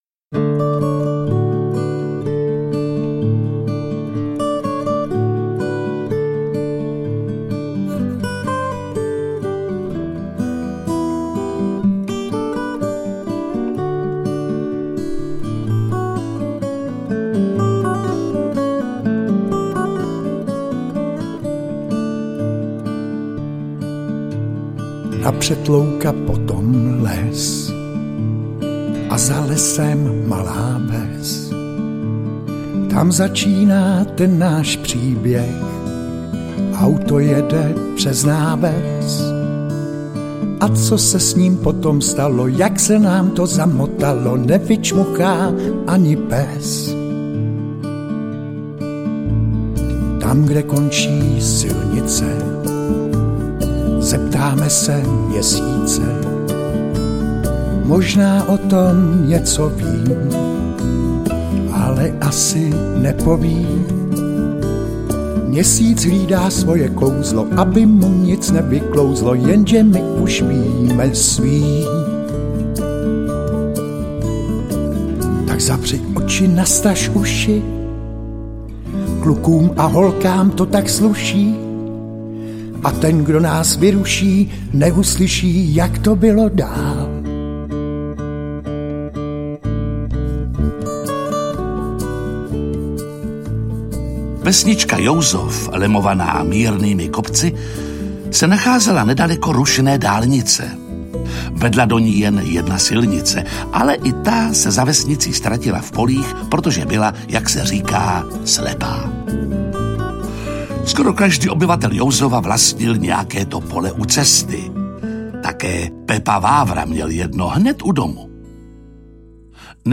Kouzelný měsíc audiokniha
Ukázka z knihy